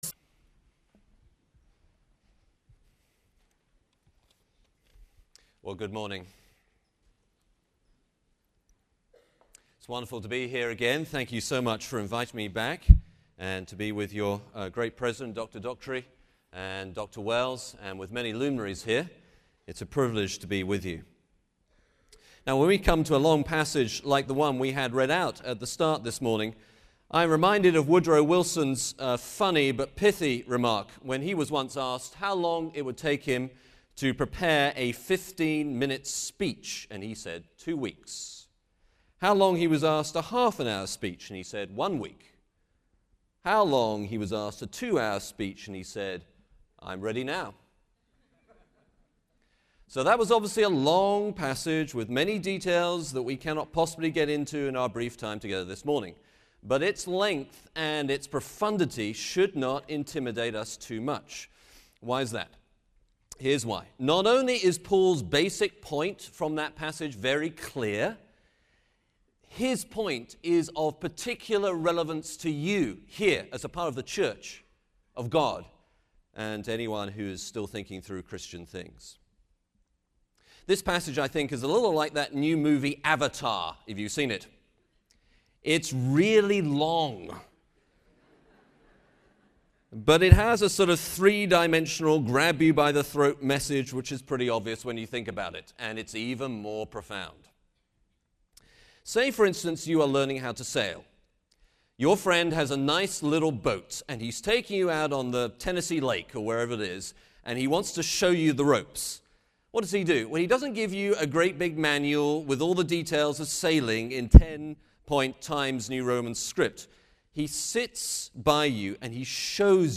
Union University, a Christian College in Tennessee